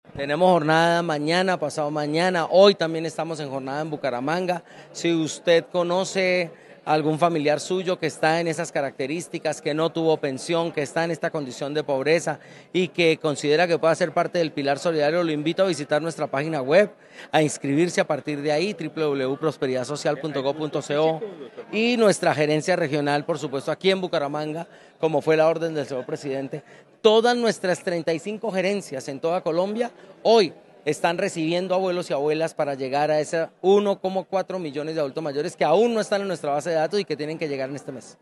Mauricio Rodríguez, director de prosperidad social